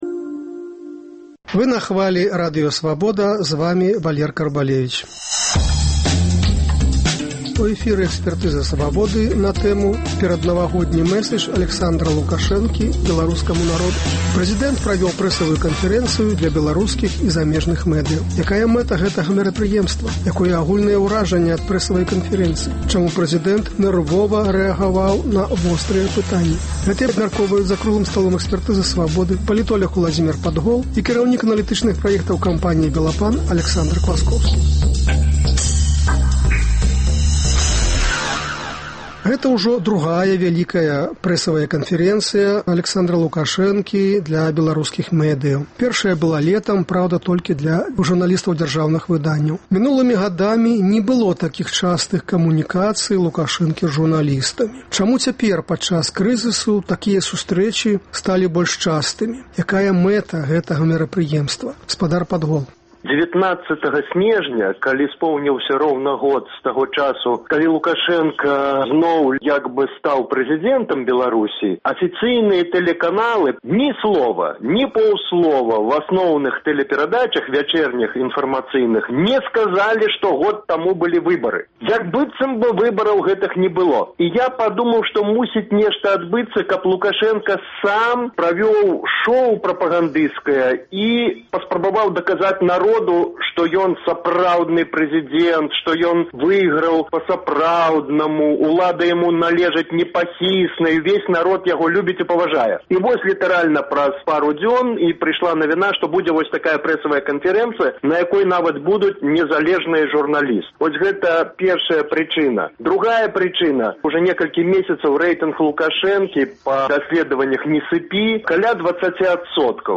Гэта абмяркоўваюць за круглым сталом